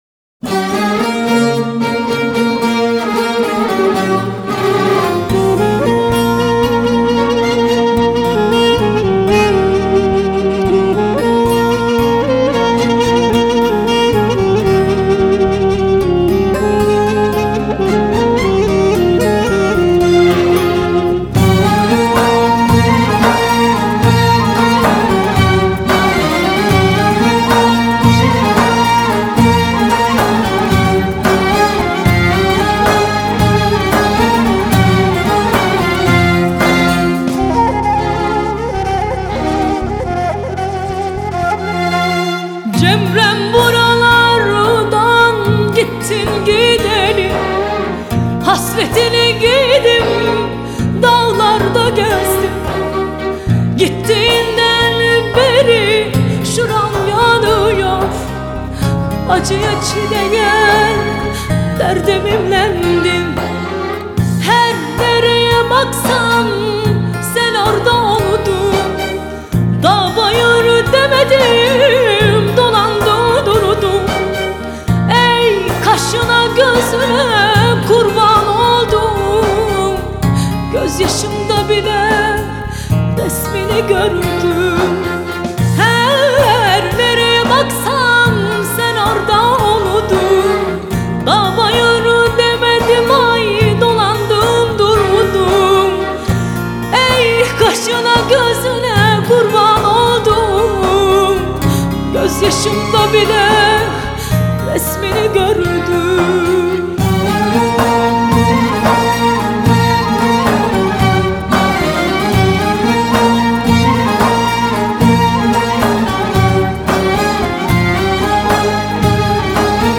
آهنگ ترکیه ای آهنگ غمگین ترکیه ای آهنگ نوستالژی ترکیه ای